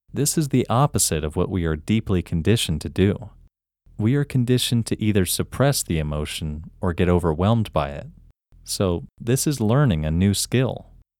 IN – First Way – English Male 9
IN-1-English-Male-9.mp3